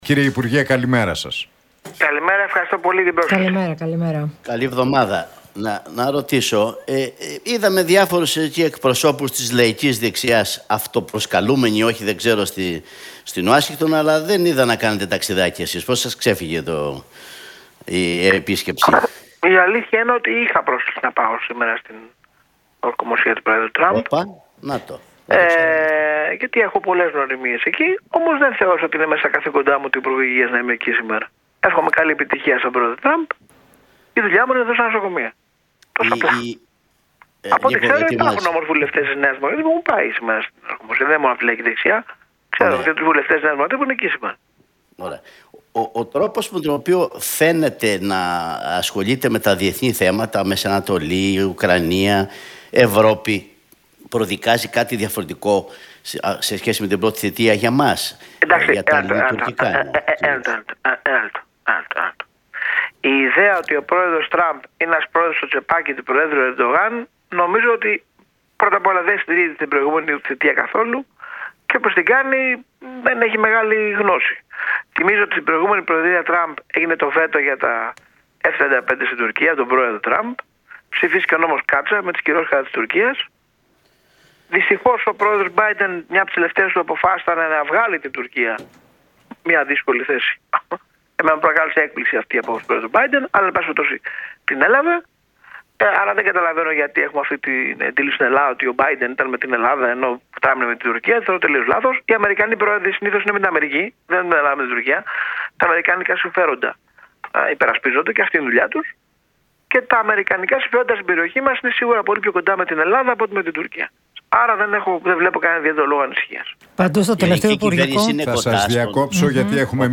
Γεωργιάδης στον Realfm 97,8: Είχα πρόσκληση για την ορκωμοσία του Τραμπ, αλλά η δουλειά μου είναι εδώ στα νοσοκομεία